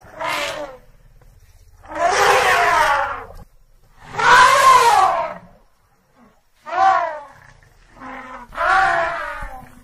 Hayvanlar